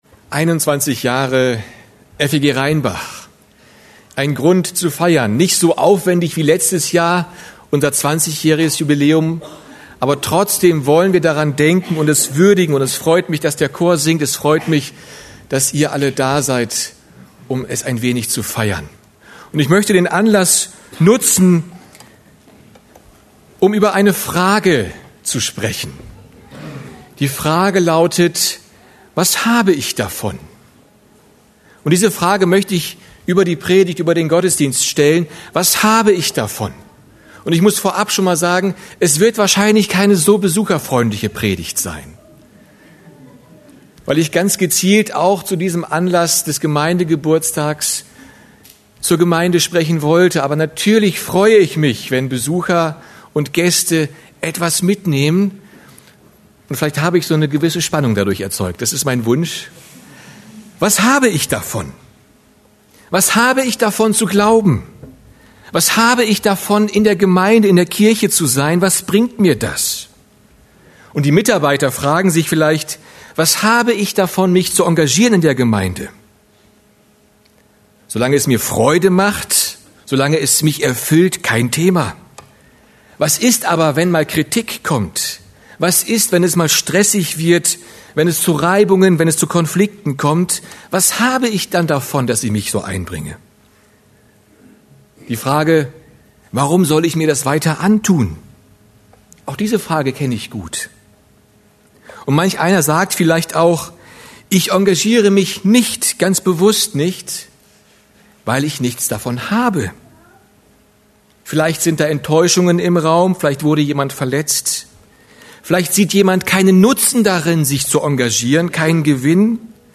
Serie: Einzelpredigten